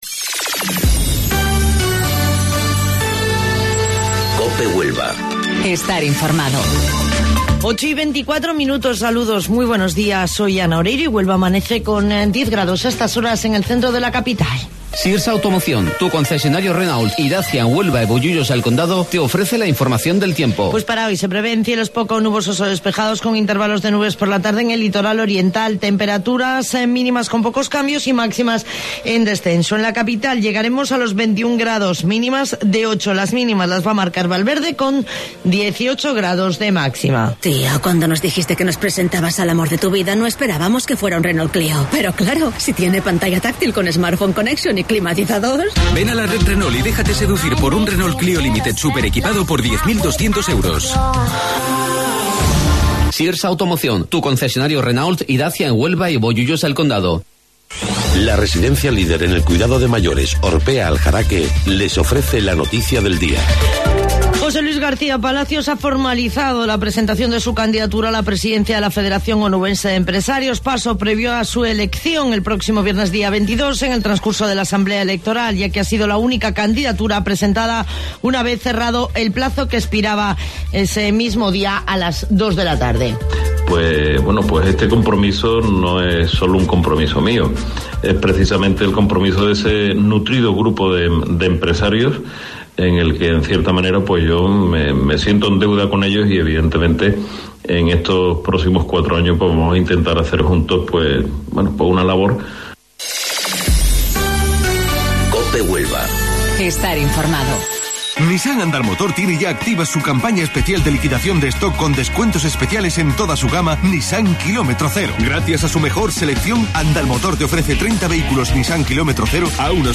AUDIO: Informativo Local 08:25 del 20 de Marzo